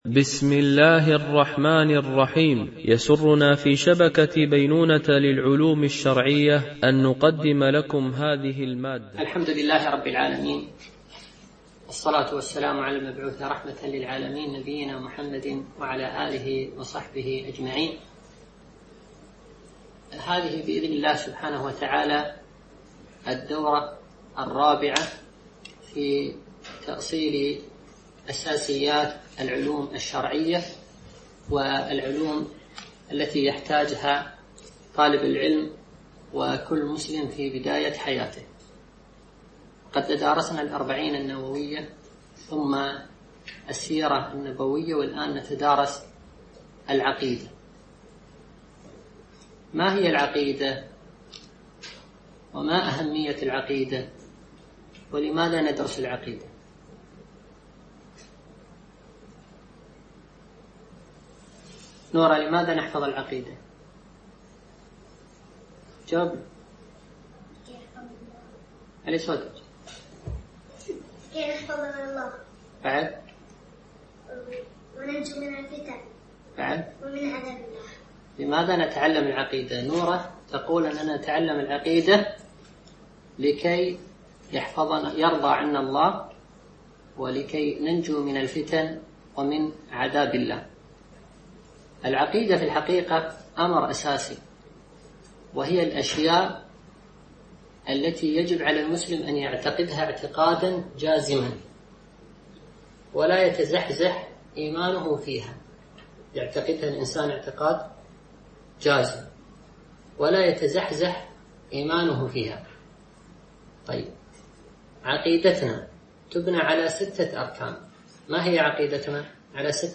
دورة علمية عن بعد